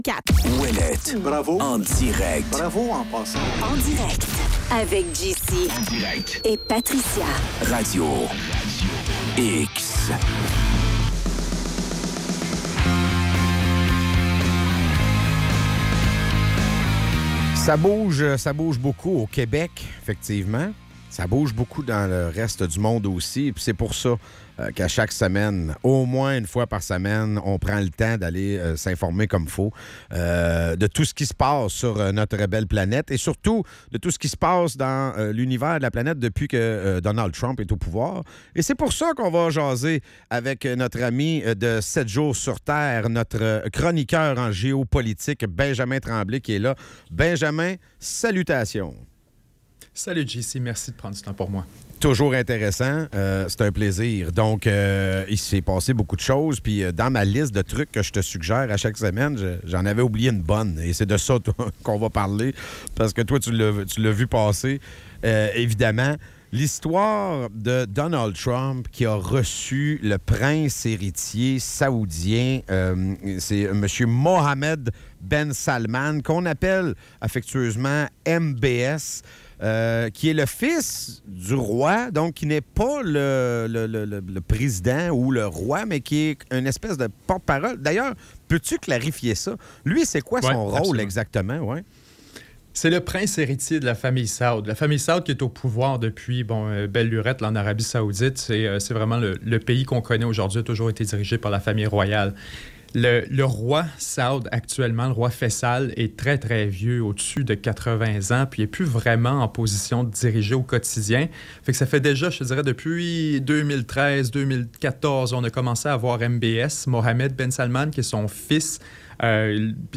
La chronique